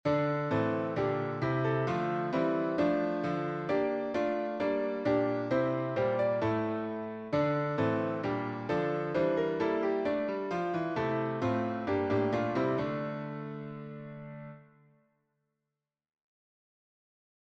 A Hasidic tune